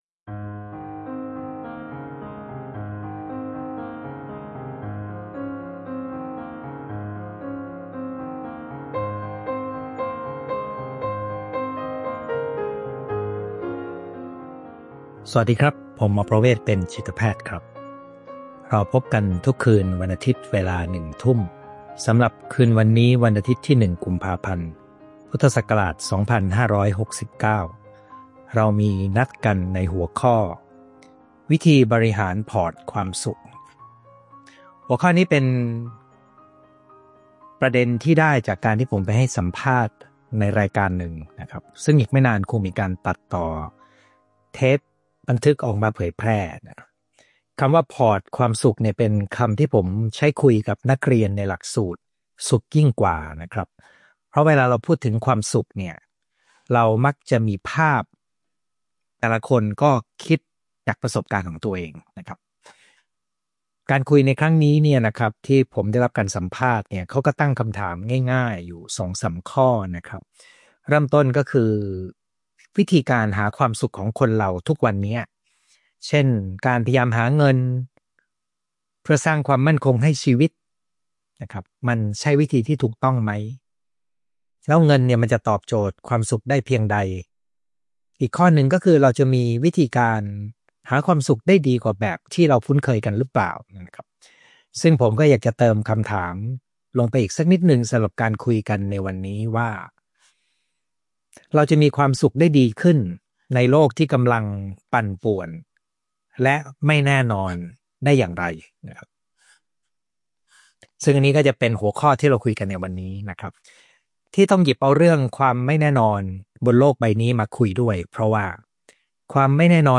ไลฟ์ประจำวันอาทิตย์ที่ 22 กุมภาพันธ์ 2569 เวลาหนึ่งทุ่ม